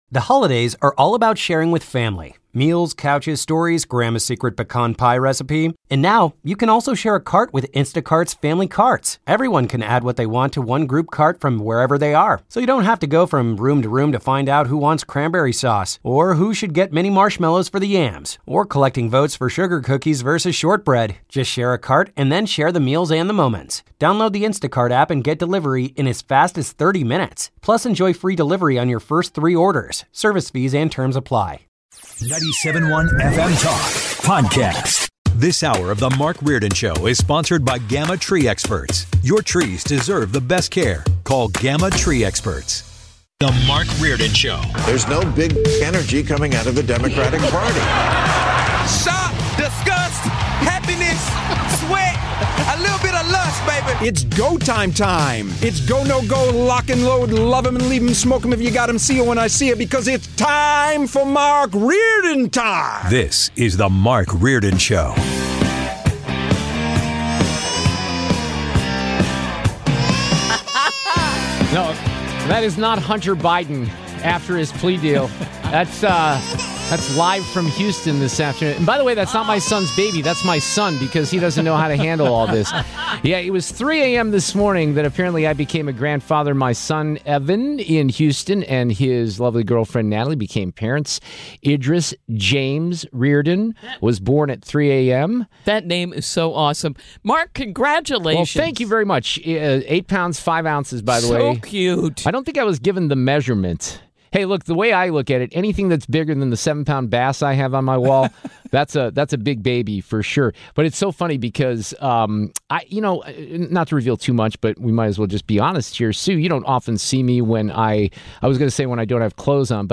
He is then joined by Brian Kilmeade, the co-host of FOX and Friends; the host of One Nation with Brian Kilmeade (Saturdays at 8pm Central) and the host of The Brian Kilmeade Show on KFTK every morning.